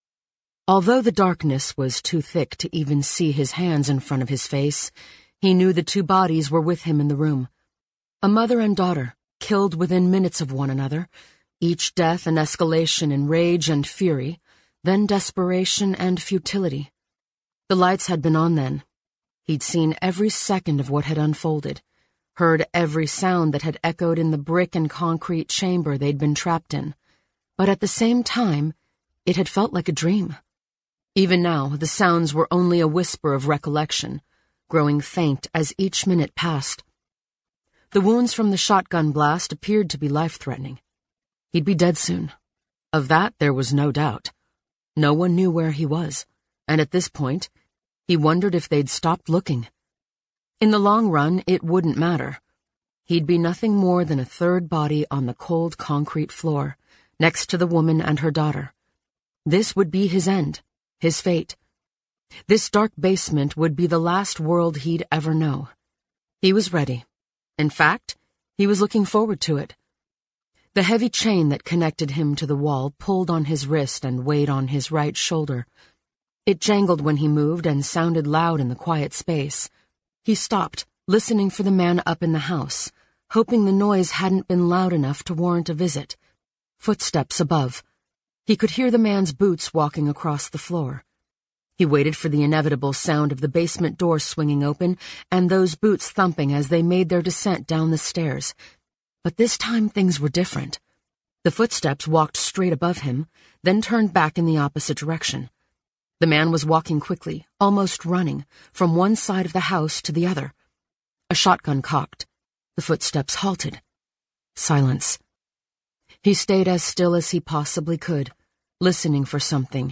Audiobooks
Her voice range spans between teens, 20s – 30s and middle age, and her accents most known for are American English, British English, Australian/ South African English, American South, French, Italian, New York, South American, Spanish.